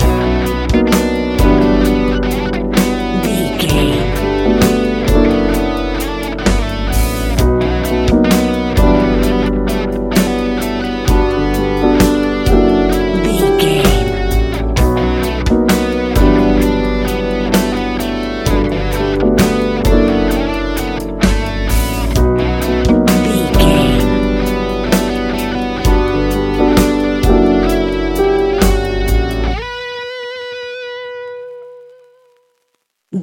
Ionian/Major
chilled
laid back
Lounge
sparse
new age
chilled electronica
ambient
atmospheric